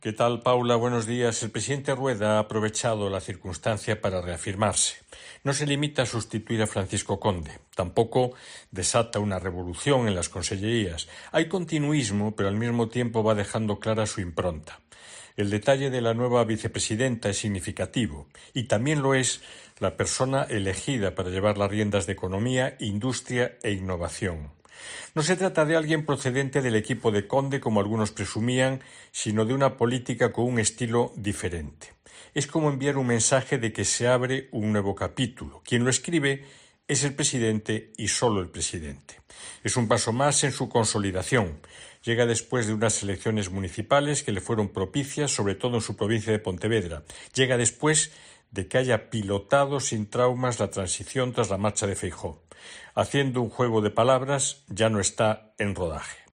analista político